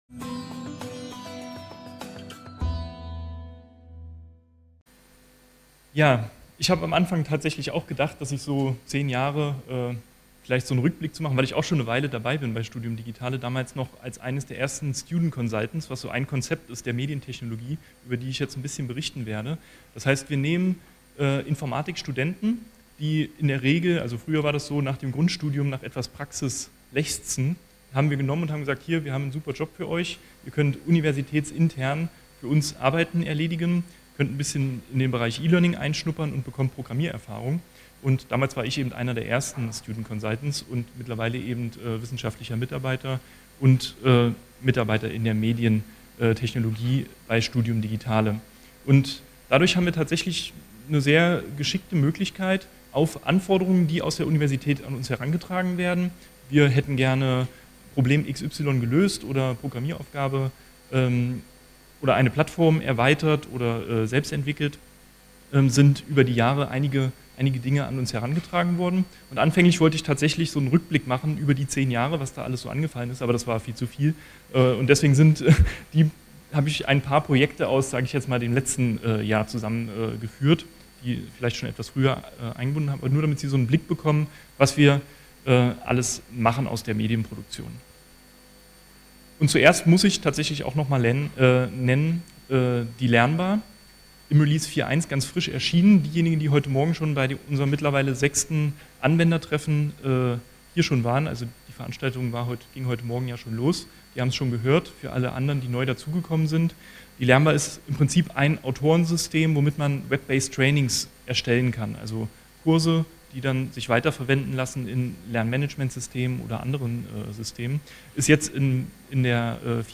Goethe-Universität Ort Casino Campus Westend, Renate von Metzler Saal Datum 22.06.2015 @ 16:10